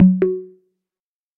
Звуки подключения, отключения
Сеть пропала, проверьте кабель